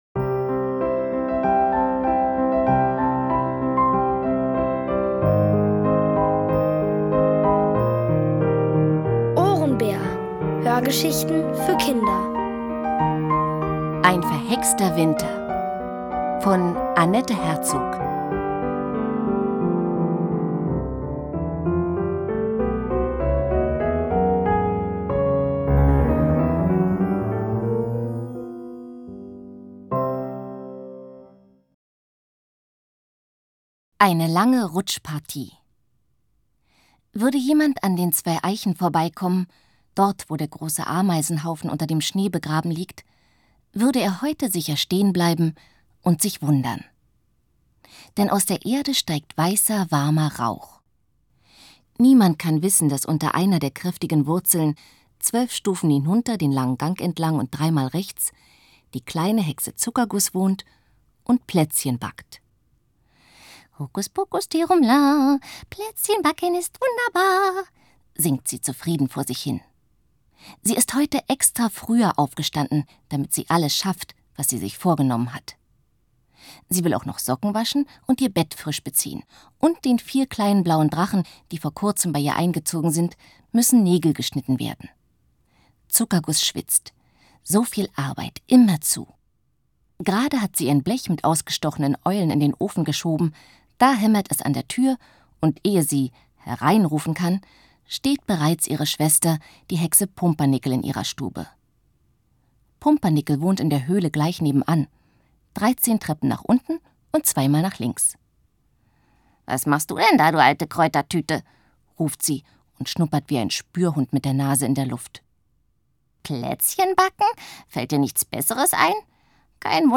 Von Autoren extra für die Reihe geschrieben und von bekannten Schauspielern gelesen.